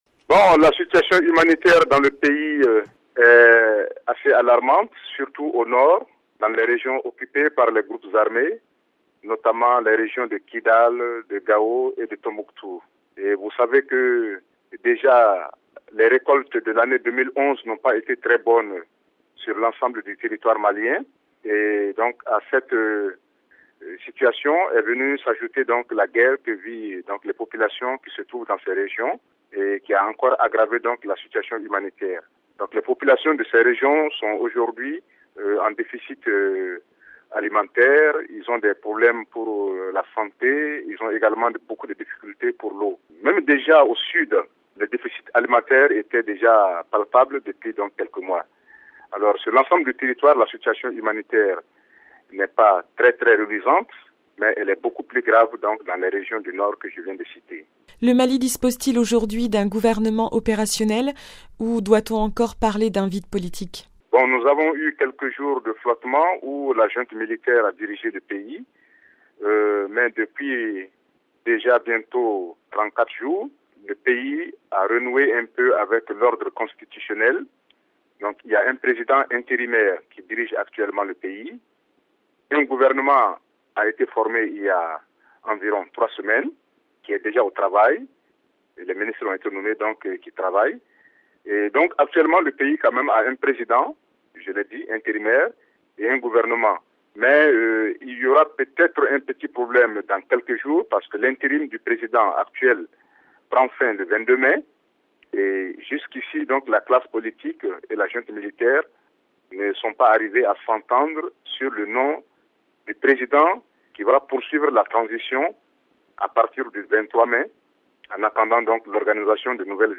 a pu joindre, par téléphone